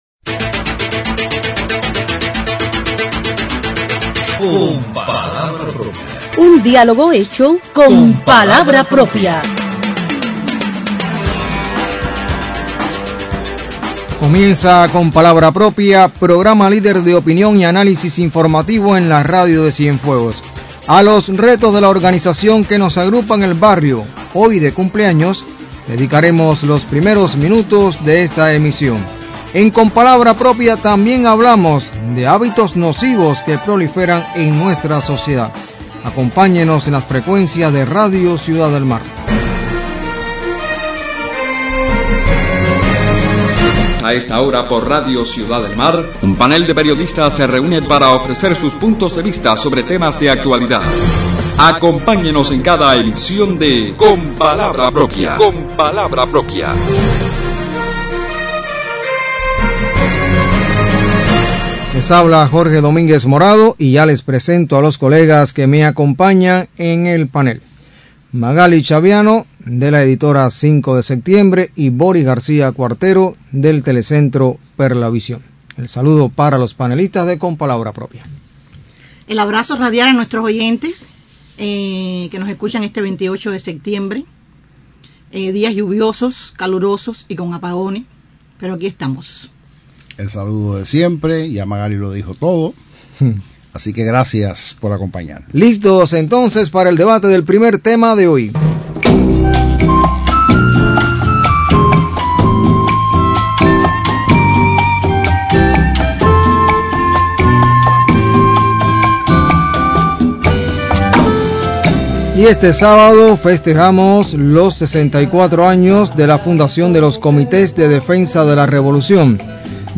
Una reflexión del reconocido sicólogo Manuel Calviño sobre hábitos nocivos que están proliferando en la sociedad cubana motiva el debate de los panelistas de Con palabra propia, en una emisión en la que también comentan sobre los retos de los Comités de Defensa de la Revolución para adaptarse a las nuevas realidades de la Cuba de estos tiempos.